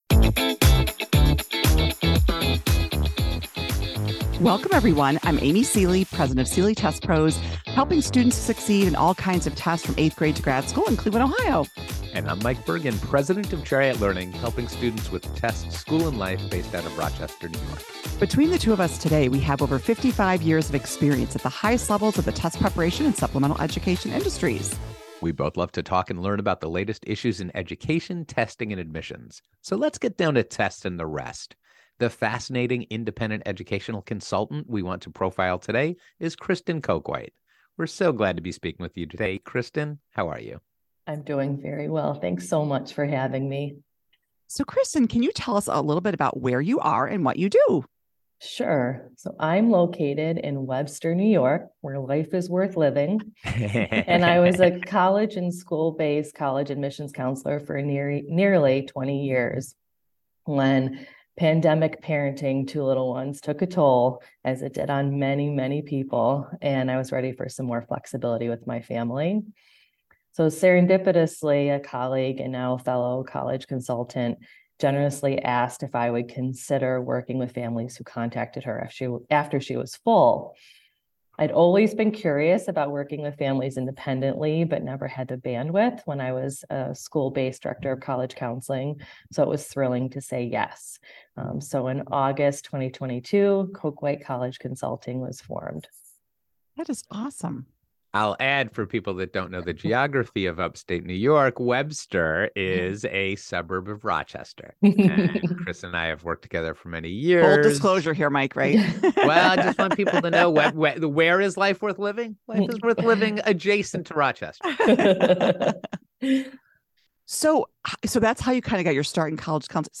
Listen to my interview about the path to college consulting on the popular Tests and the Rest podcast.